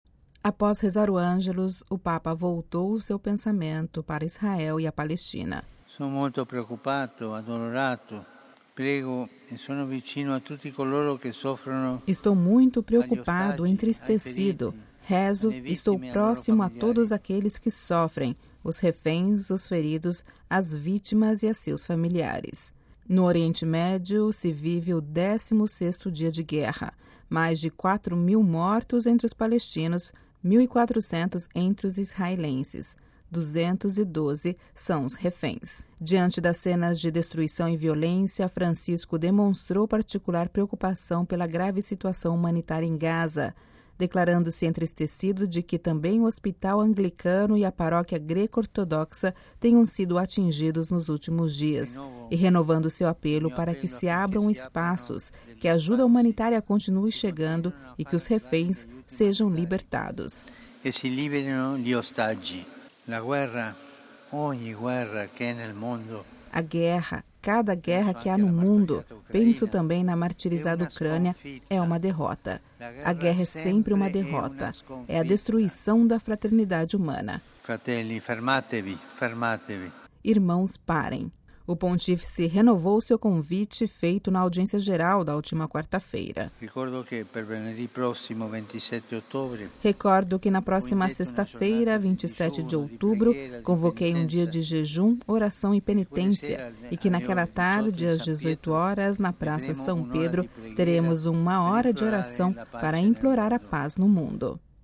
Ouça a reportagem completa com a voz do Papa Francisco